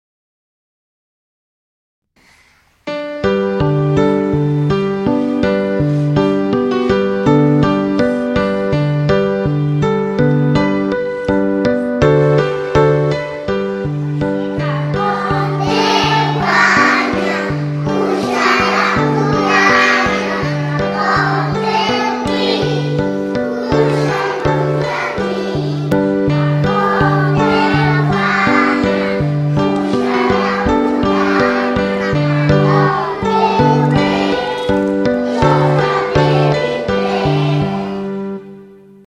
“CARGOL TREU BANYA”, cantat per alumnes de p-3 (4 classes d’uns 20 alumnes)